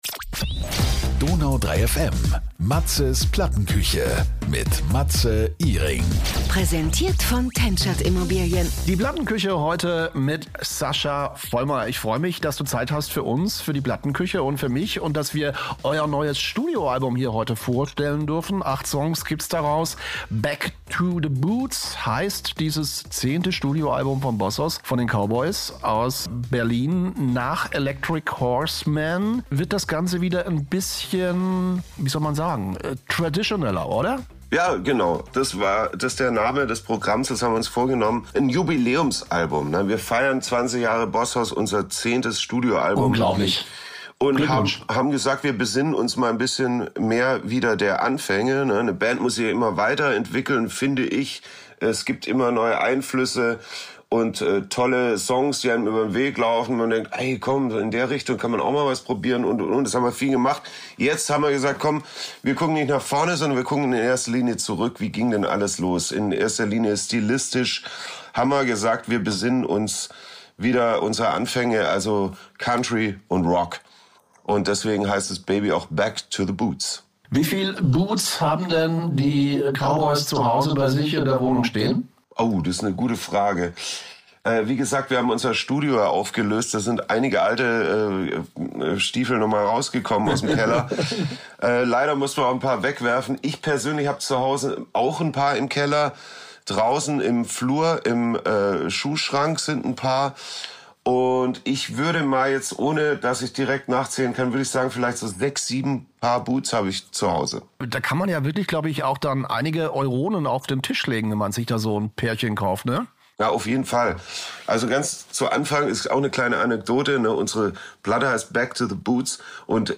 Außerdem berichtet er von der unglaublichen Zusammenarbeit mit Arnold Schwarzenegger, die von einer Charity-Gala über ein Wohnzimmerkonzert bis hin zu „I’ll Be Back“ führte. Das Interview streift große Momente der Bandgeschichte, legendäre Tour-Erlebnisse, Freundschaft, Durchhaltevermögen, „Win-Win“-Spirit und einen klaren Blick nach vorne.